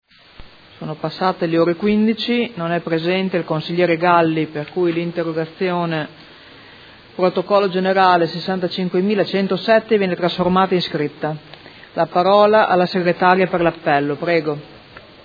Presidente — Sito Audio Consiglio Comunale
Seduta del 27/09/2018. Comunicazione di risposte scritta a interrogazione del Consigliere Galli (FI) avente per oggetto: Il “nuovo” Assessore completerà le promesse lasciate in sospeso dal “vecchio” assessore Gabriele Giacobazzi? – EDICOLA di Corso Duomo